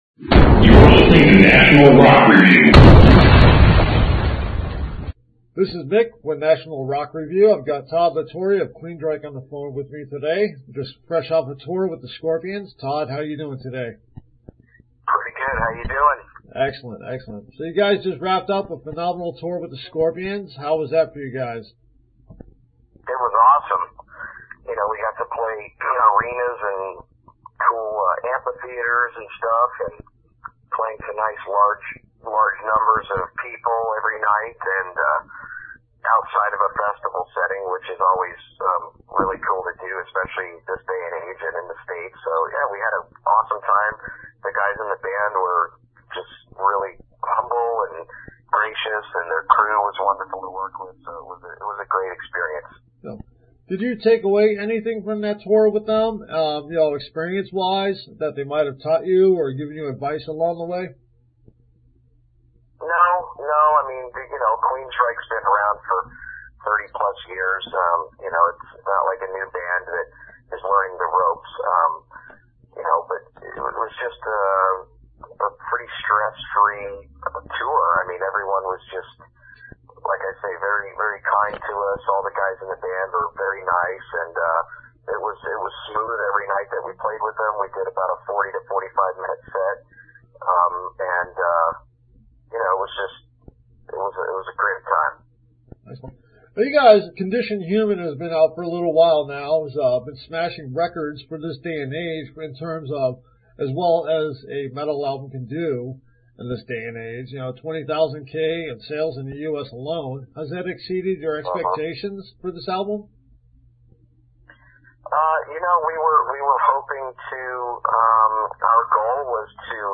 Todd La Torre of Queensryche interview